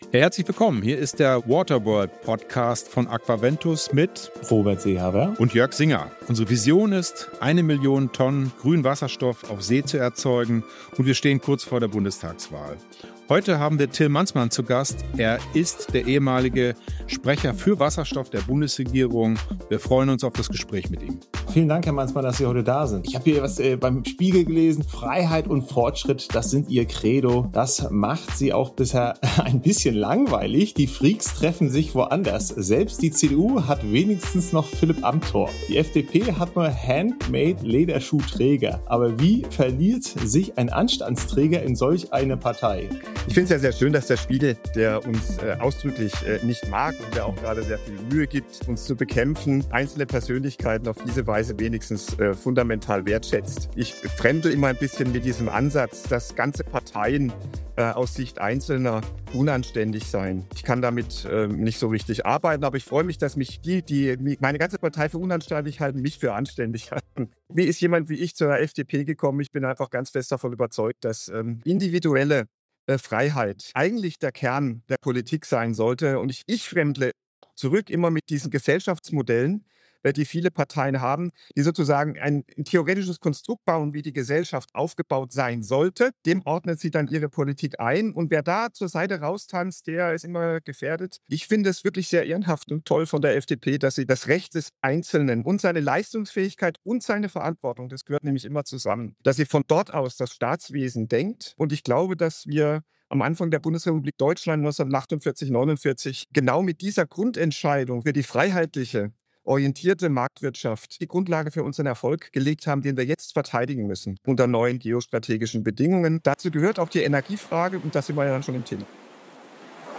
Im Gespräch mit Waterworld sieht der ehemalige Wasserstoffbeauftragte der Bundesregierung große Potentiale für Wasserstoff - auch für die Länder des globalen Südens.